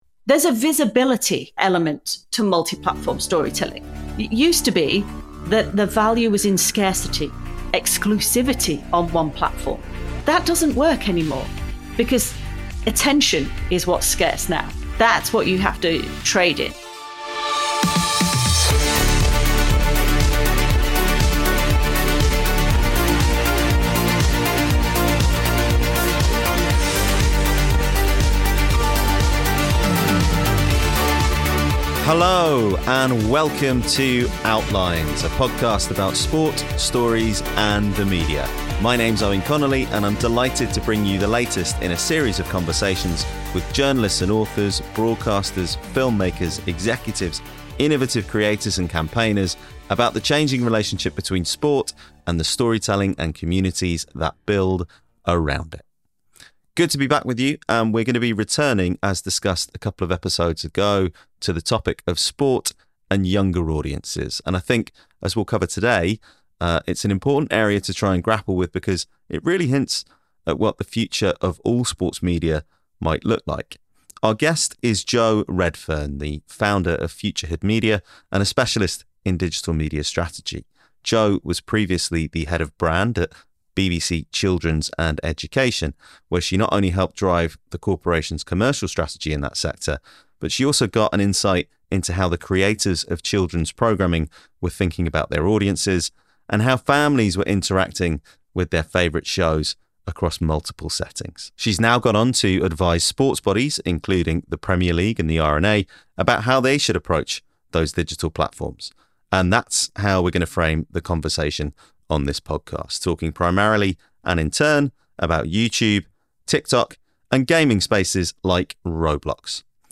In another conversation about sport and younger audiences